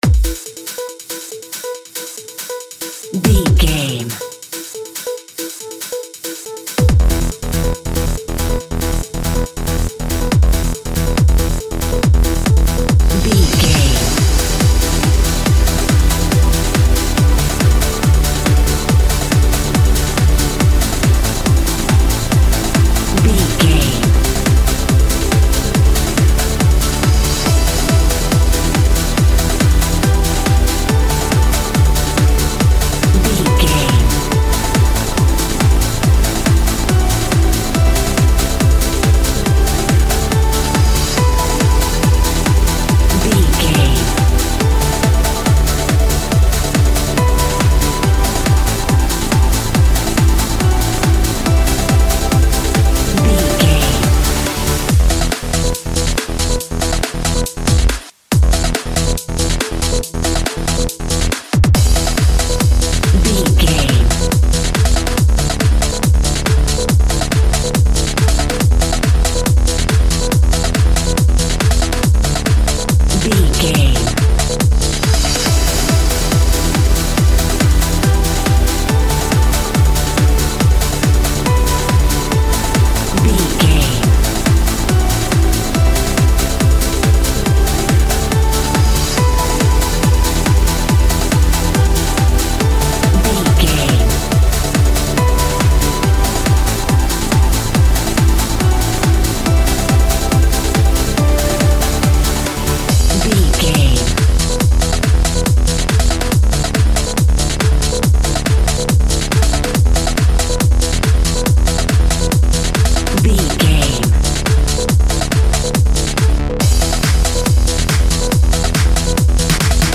Chart Club Modern House Music Cue.
Epic / Action
Fast paced
Aeolian/Minor
dark
futuristic
groovy
synthesiser
drum machine
electric piano
house
electro dance
instrumentals
synth bass
upbeat